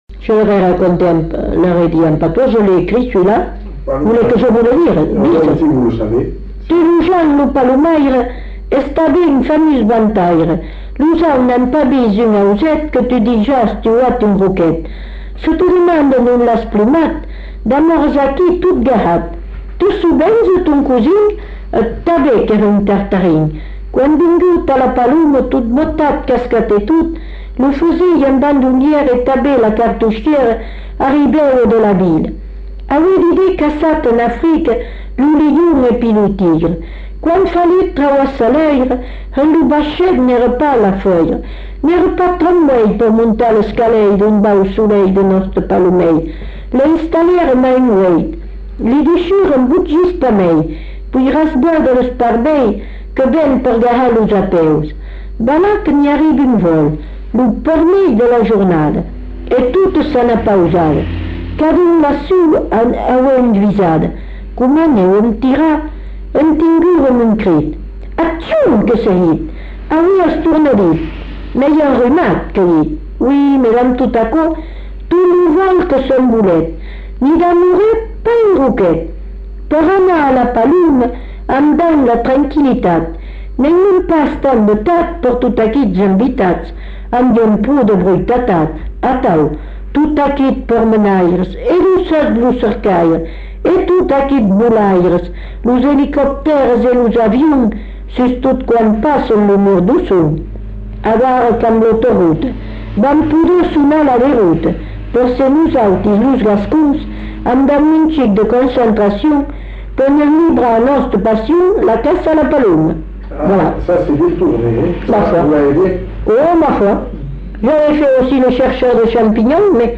Aire culturelle : Bazadais
Genre : conte-légende-récit
Type de voix : voix de femme
Production du son : lu
Classification : monologue
Notes consultables : En vers rimés.